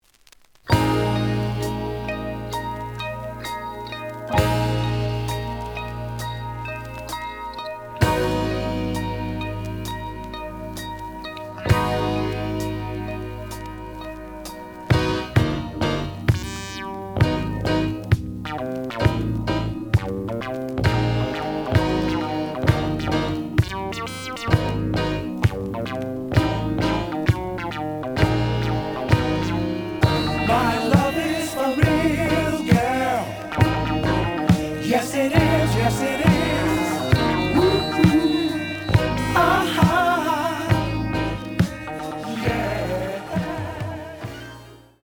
試聴は実際のレコードから録音しています。
●Genre: Funk, 70's Funk
●Record Grading: VG+ (盤に若干の歪み。多少の傷はあるが、おおむね良好。)